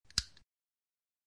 switch.mp3